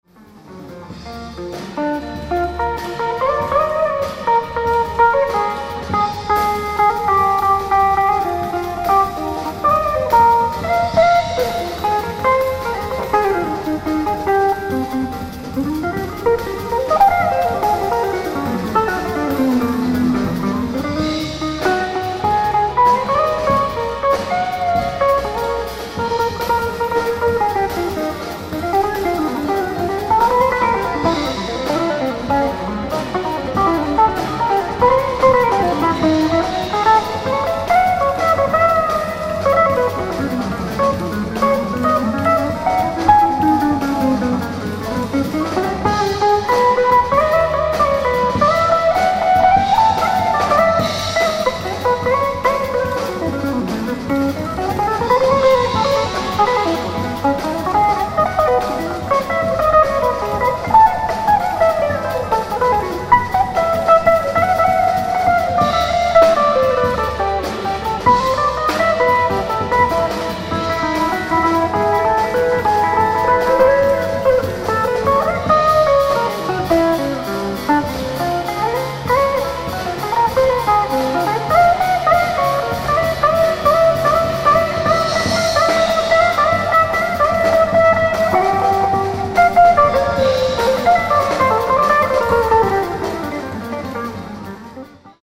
ライブ・アット・サーカス・クローネ、ミュンヘン、ドイツ 06/05/2002
３時間１０分に及んだ圧巻のライブ！！
※試聴用に実際より音質を落としています。